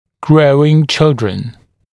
[‘grəuɪŋ ‘ʧɪldr(ə)n][‘гроуин ‘чилдр(э)н]растущие дети